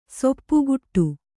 ♪ soppuguṭṭu